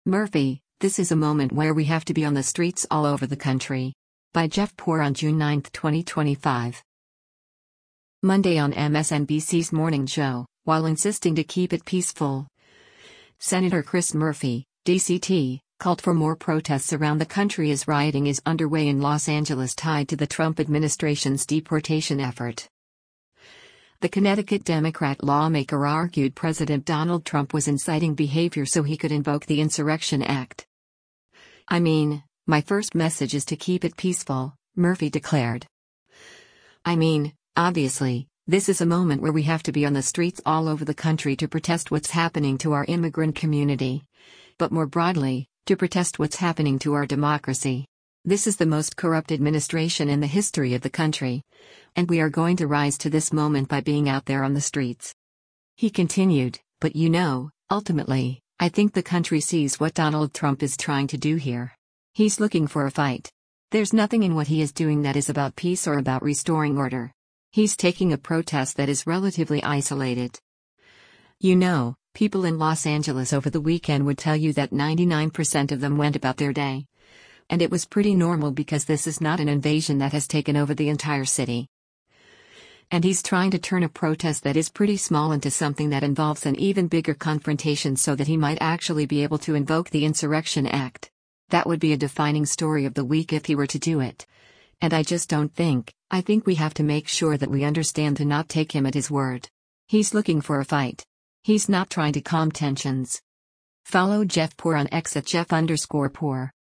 Monday on MSNBC’s “Morning Joe,” while insisting to “keep it peaceful,” Sen. Chris Murphy (D-CT) called for more protests around the country as rioting is underway in Los Angeles tied to the Trump administration’s deportation effort.